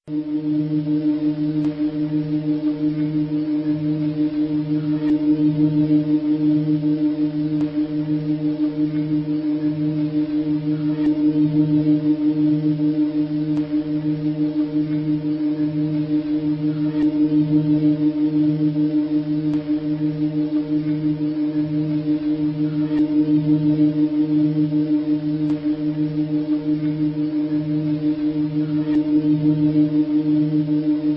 classichum.wav